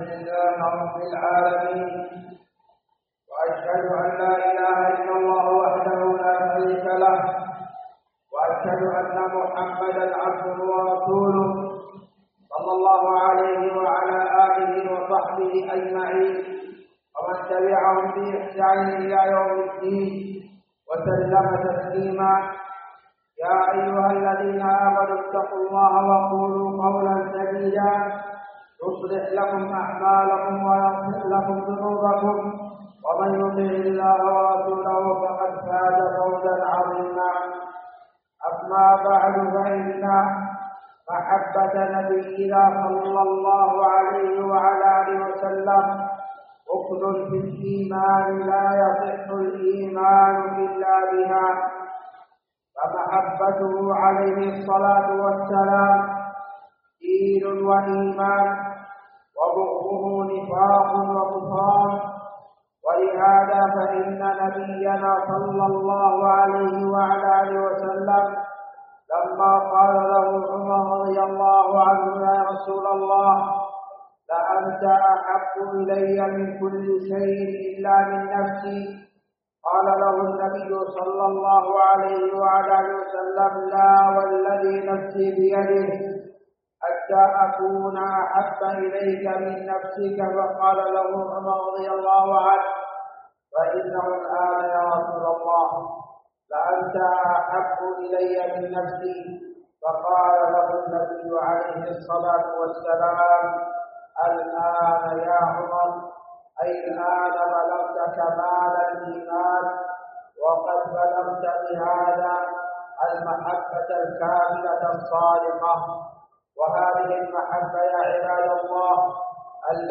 خطبة
جامع الملك عبدالعزيز باسكان الخارش بصامطة